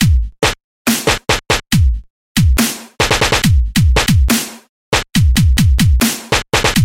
重型变化踢踏小号，带鼓点
描述：同样的事情，只是用打击乐器。
Tag: 140 bpm Dubstep Loops Drum Loops 1.15 MB wav Key : Unknown